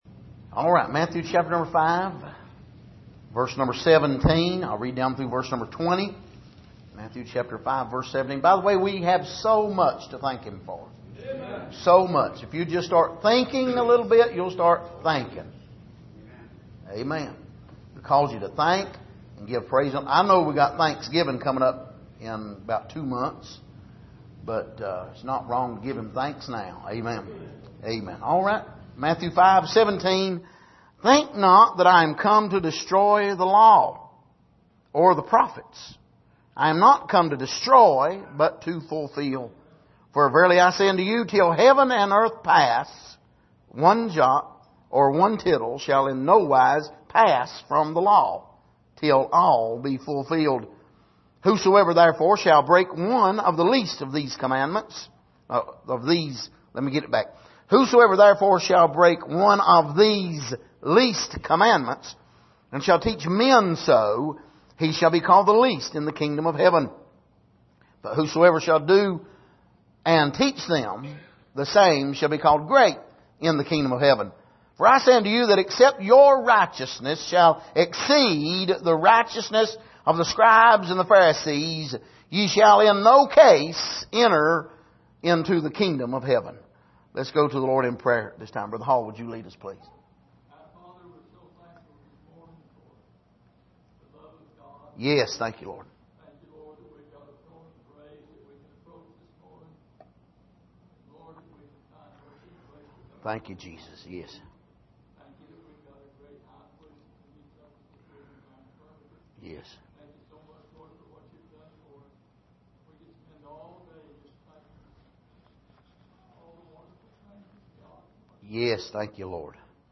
Passage: Matthew 5:17-20 Service: Sunday Morning